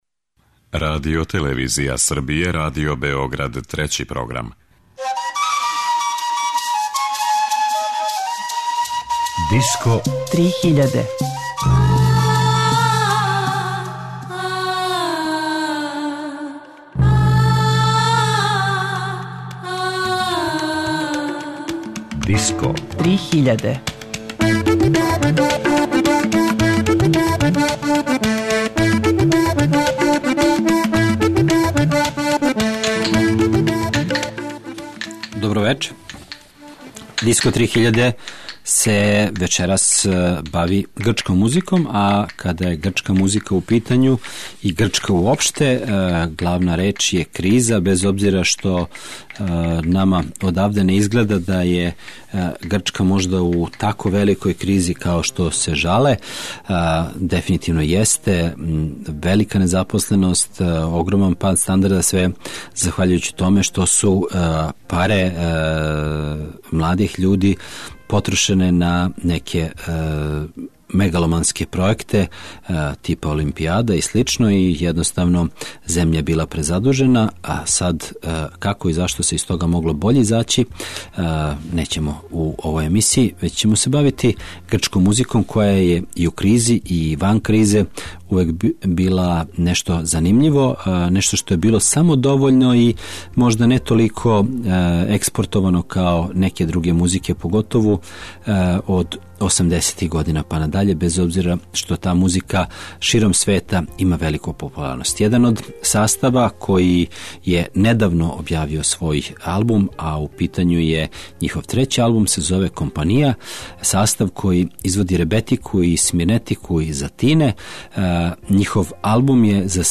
Грчка музика
Ове неде љ е слушаћете разноврсну грчку традиционалну и world музику.